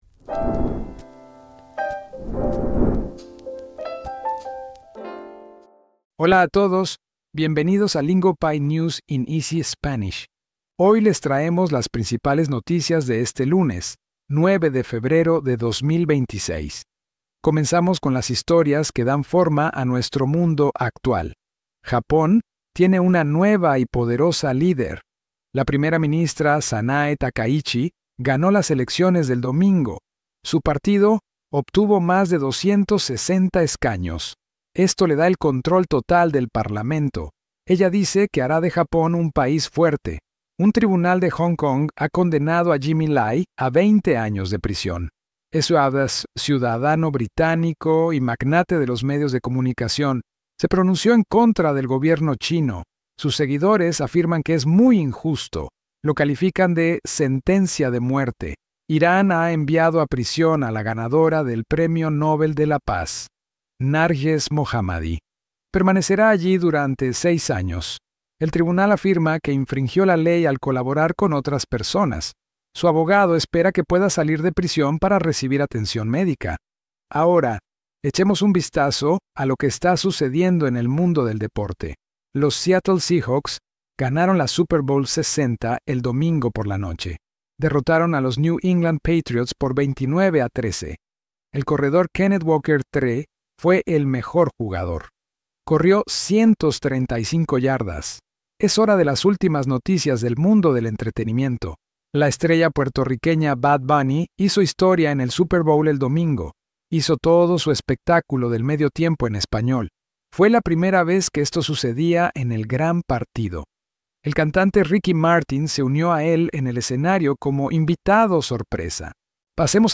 Lingopie’s News in Easy Spanish gives you real-world Spanish listening practice through today’s biggest headlines. We keep the Spanish clear and beginner-friendly, so you can catch the meaning in real time instead of chasing every sentence.